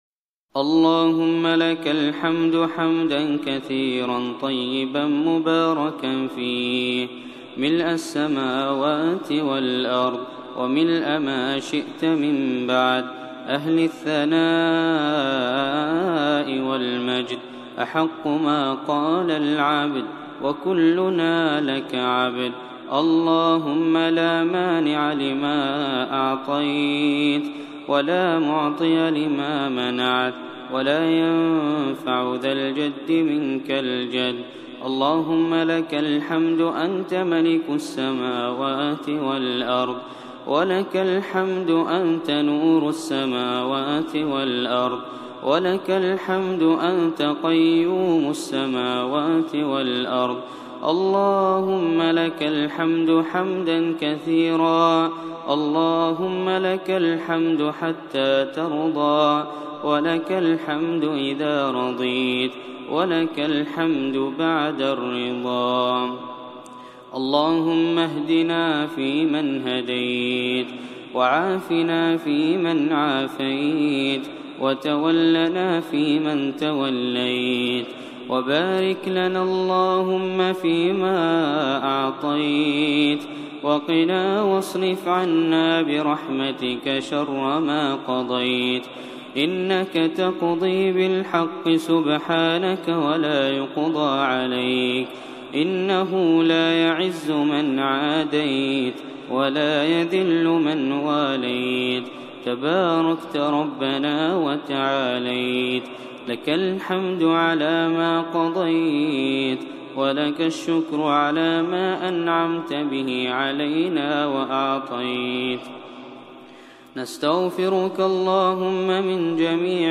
دعاء
دعاء خاشع ومؤثر بصوت الشيخ هزاع البلوشي.
تسجيل لدعاء خاشع ومميز بصوت الشيخ هزاع البلوشي.